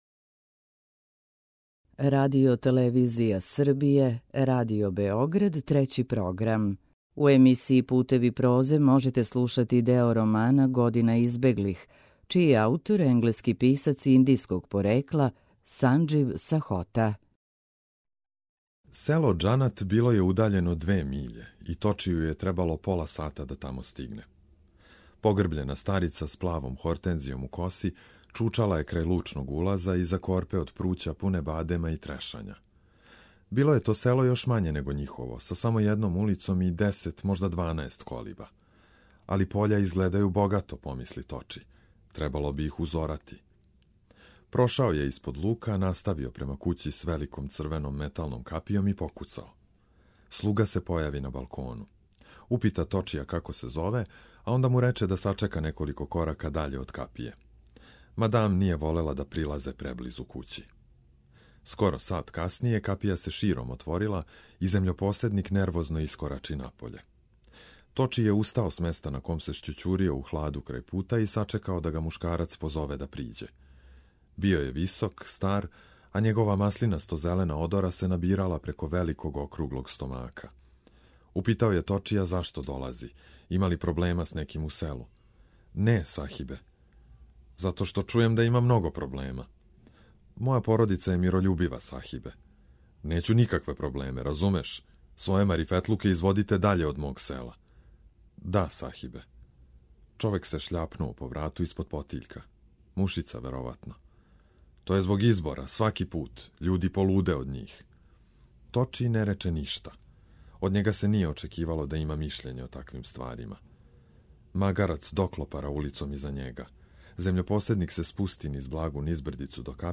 У циклусу Путеви прозе ове недеље можете слушати део романа „Година избеглих”, чији је аутор британски писац индијског порекла Санџив Сахота.